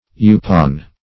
Youpon \You"pon\, n. (Bot.)